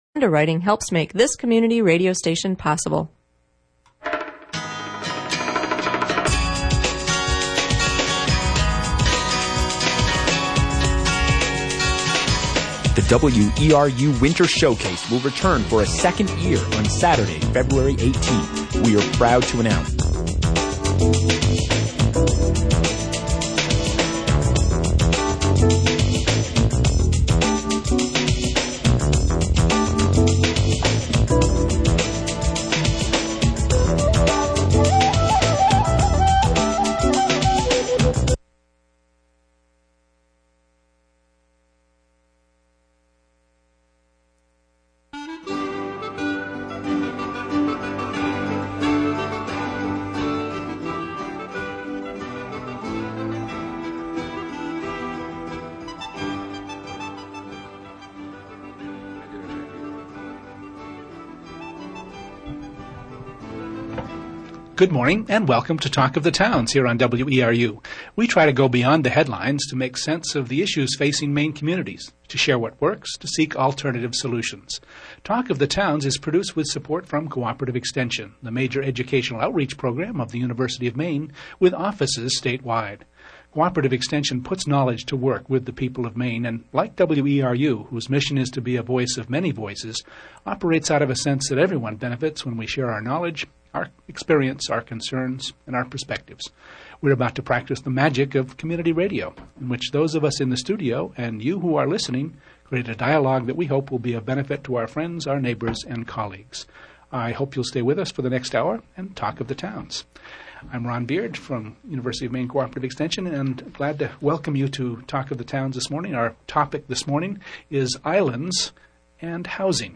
Live & local call-in on civic issues.
During the final segment of the program the phone lines were opened to callers.